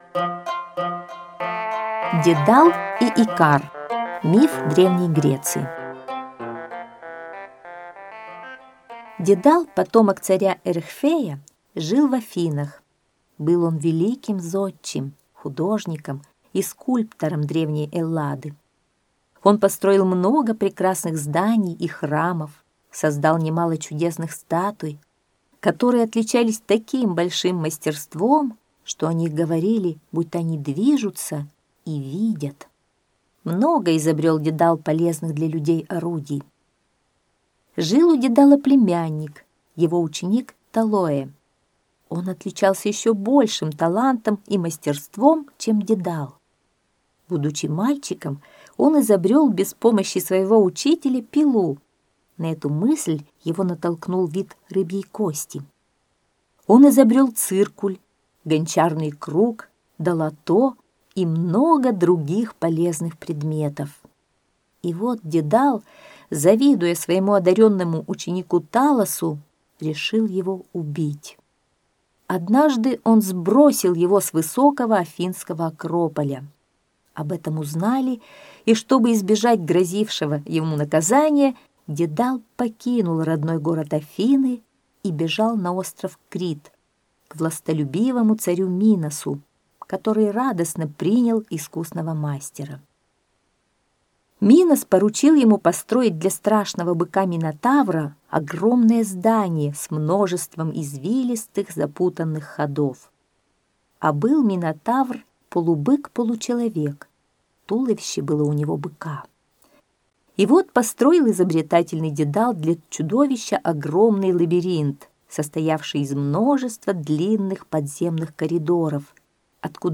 Дедал и Икар - аудио миф Древней Греции - слушать онлайн